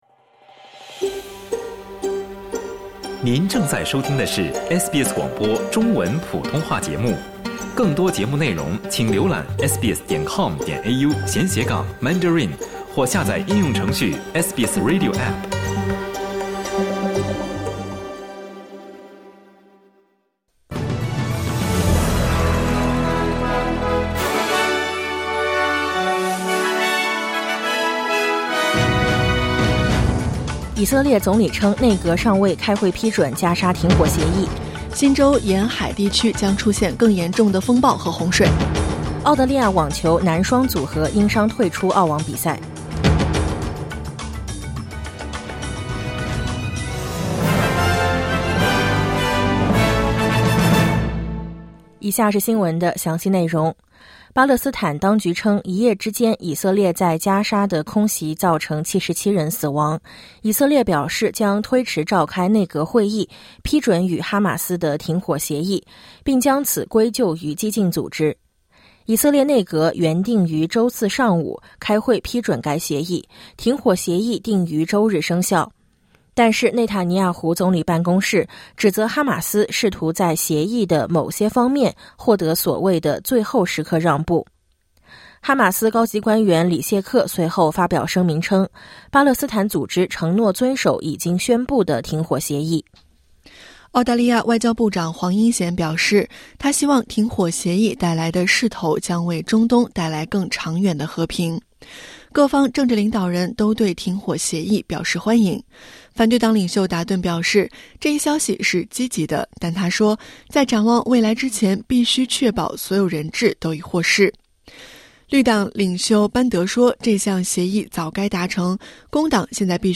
SBS早新闻（2025年1月17日）